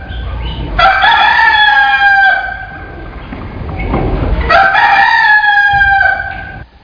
rooster.mp3